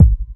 kick 17.wav